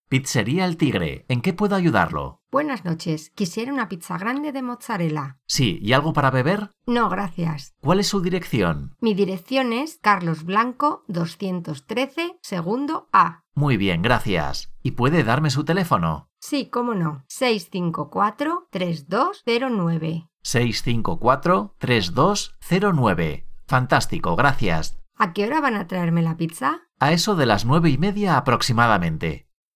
Recording: 0032 Level: Beginners Spanish Variety: Spanish from Spain
Transcribe the whole conversation by writing word by word what the speakers say.